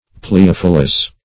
Search Result for " pleiophyllous" : The Collaborative International Dictionary of English v.0.48: Pleiophyllous \Plei*oph"yl*lous\, a. [Gr.
pleiophyllous.mp3